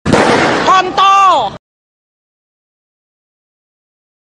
Efek Suara Dor Kontol
Kategori: Suara viral
Keterangan: Sound Effect "Dor Kontol" adalah audio meme yang viral di media sosial. Sering digunakan dalam video lucu dan editan meme.